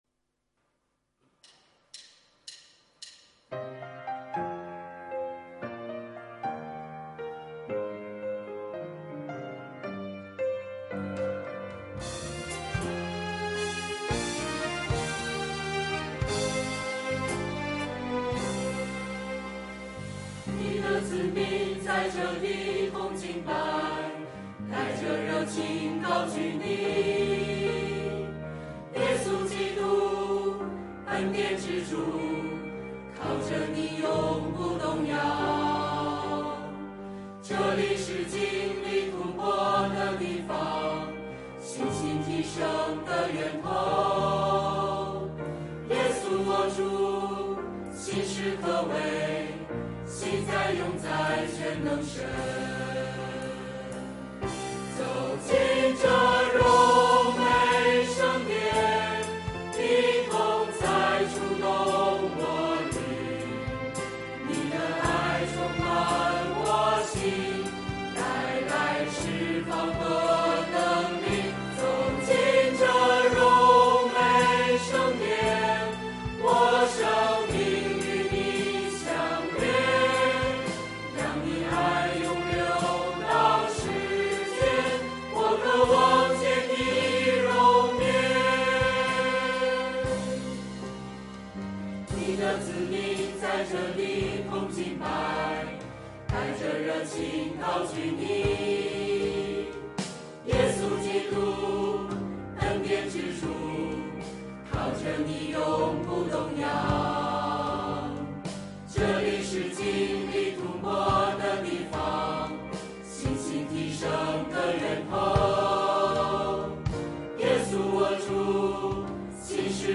团契名称: 清泉诗班 新闻分类: 诗班献诗 音频: 下载证道音频 (如果无法下载请右键点击链接选择"另存为") 视频: 下载此视频 (如果无法下载请右键点击链接选择"另存为")